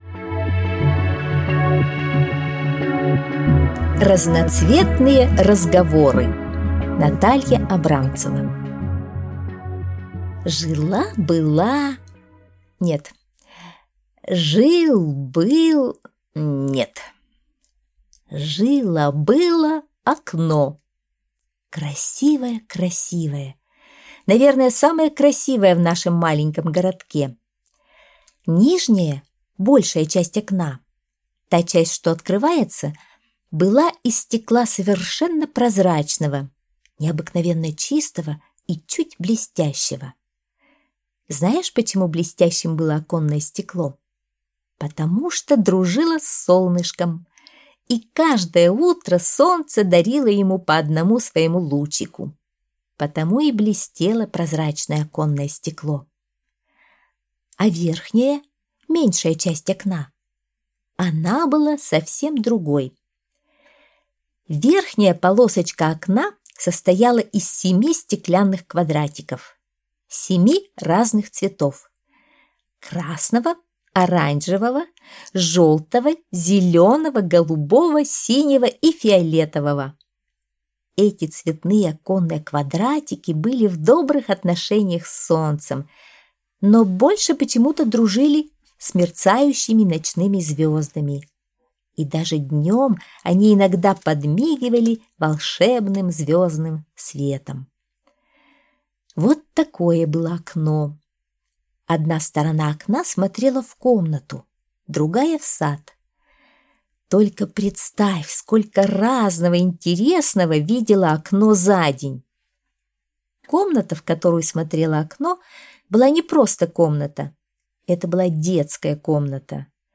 Аудиосказка «Разноцветные разговоры»